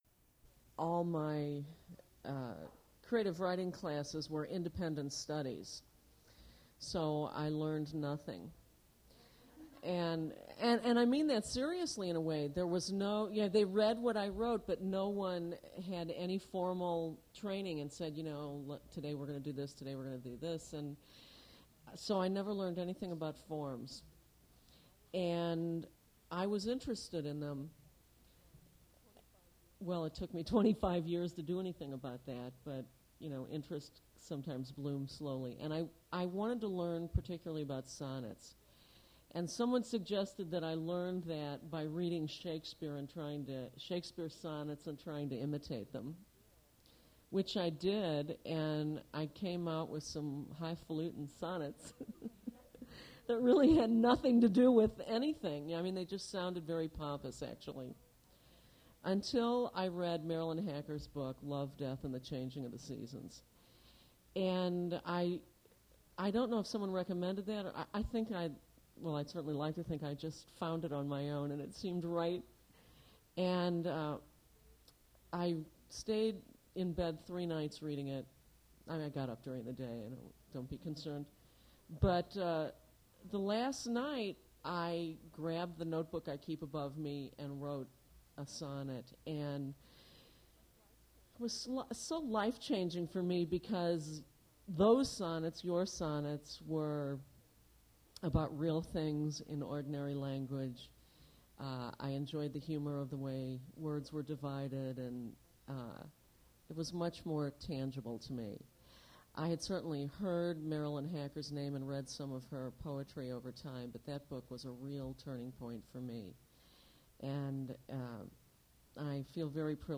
Poetry reading featuring Marilyn Hacker
Attributes Attribute Name Values Description Marilyn Hacker reading her poetry at Duff's Restaurant.
mp3 edited access file was created from unedited access file which was sourced from preservation WAV file that was generated from original audio cassette.
recording starts mid-introduction